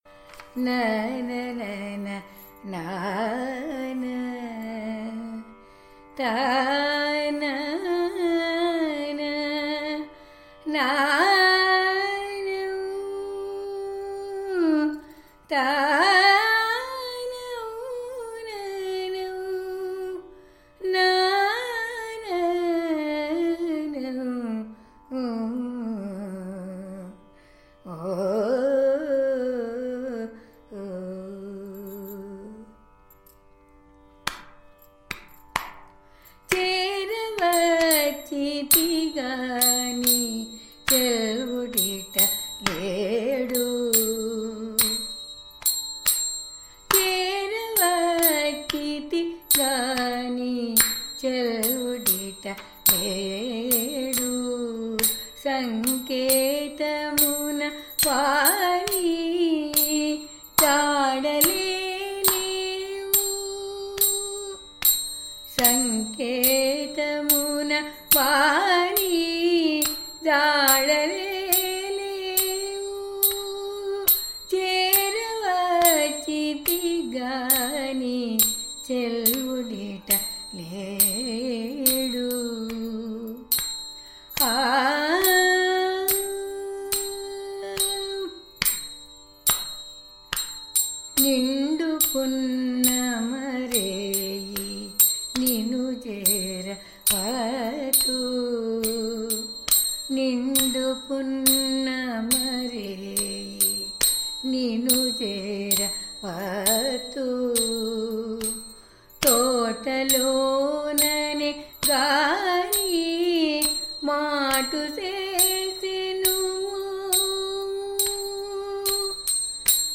చేరవచ్చితిగాని (దేశ్ రాగం)